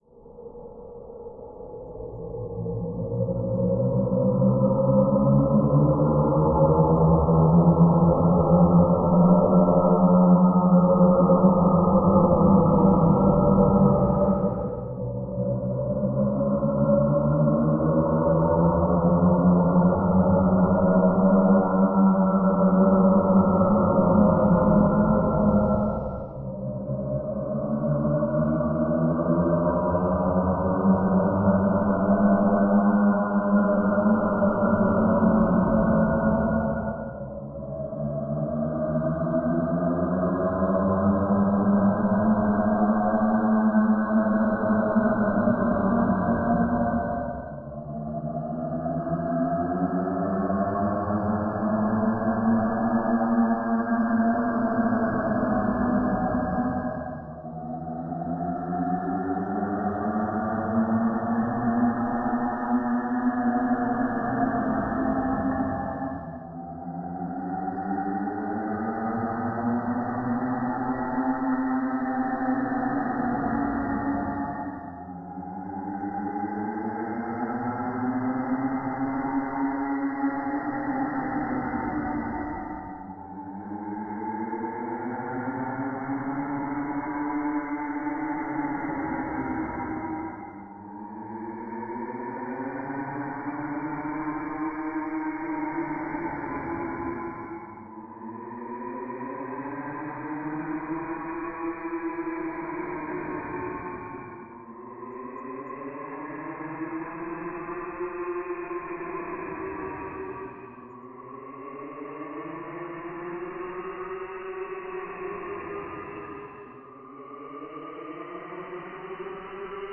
怪物的声音 " 兽吼 lvl4
描述：来自你的更酷的怪物声音 _
标签： 生物 动物 缠结 恐龙 僵尸 恐怖 轰鸣 发声 怪物 咆哮 可怕
声道立体声